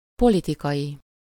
Ääntäminen
IPA : /pə.ˈlɪt.ɪ.kəl/